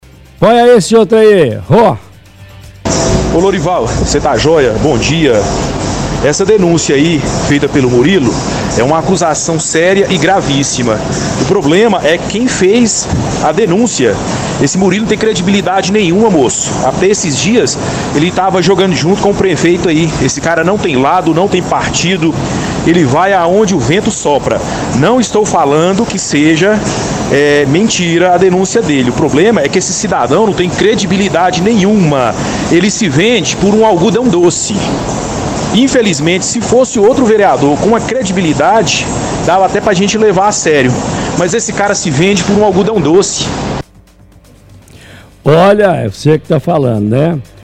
– Outro ouvinte defende que o vereador Murilo não tem credibilidade nenhuma, “ele se vende por um algodão-doce”.